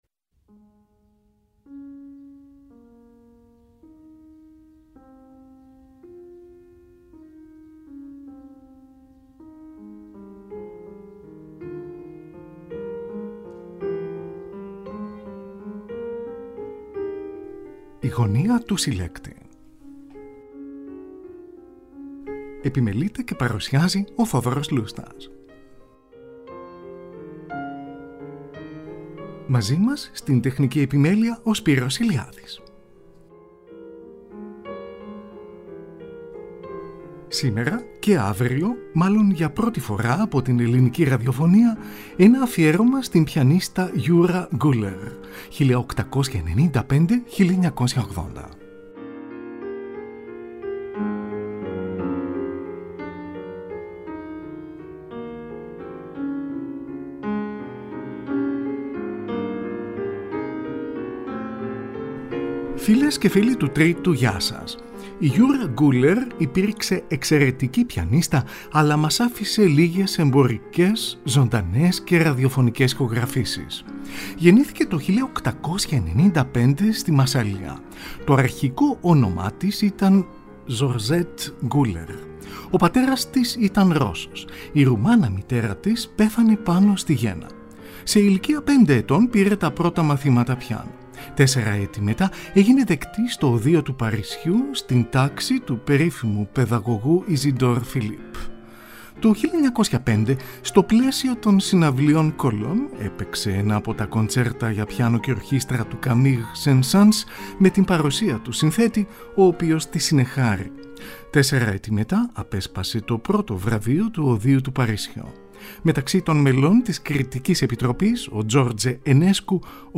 ΑΦΙΕΡΩΜΑ ΣΤΗΝ ΠΙΑΝΙΣΤΑ YOURA GULLER (1895-1980)
Ερμηνεύει έργα των François Couperin του Μεγάλου, Jean-Philippe Rameau, Johann Sebastian Bach, Mateo Albéniz, Frédéric Chopin, Enrique Granados κ.ά.
Μουσική